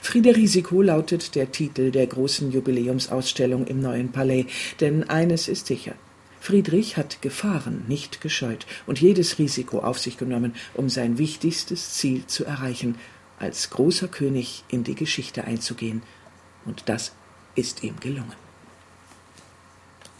sehr variabel
Mittel plus (35-65)
Kölsch